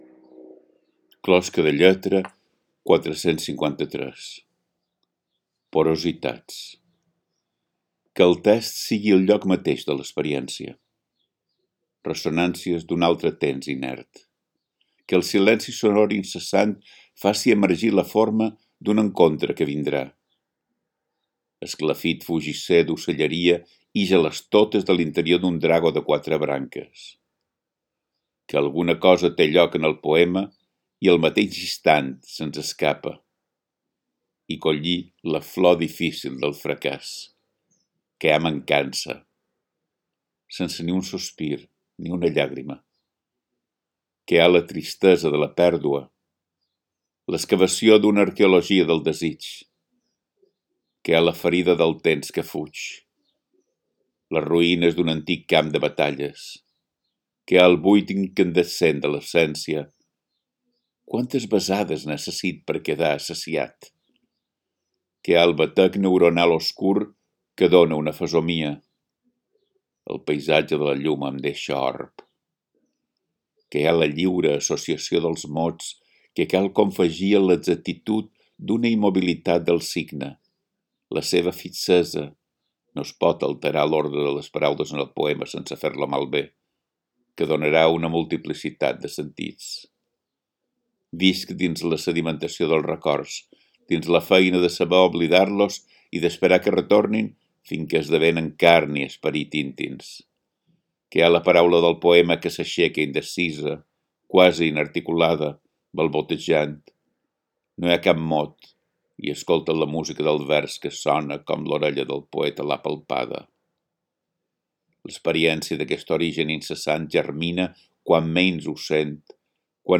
Podeu escoltar el text recitat per Biel Mesquida: